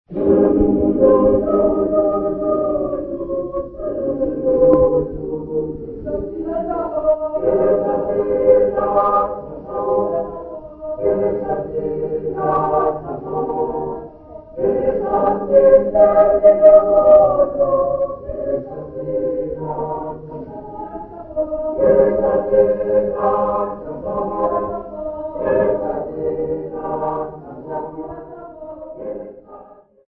Zamdela Church Congregation
Folk music
Sacred music
Field recordings
Africa South Africa Sasolburg
Unaccompanied Sotho Catholic hymn.
96000Hz 24Bit Stereo